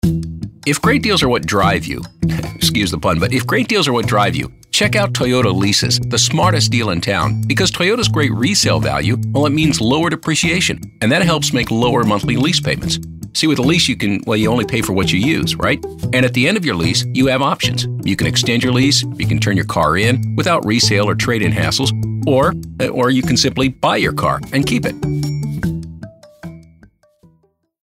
anti-announcer, conversational, friendly, homespun, real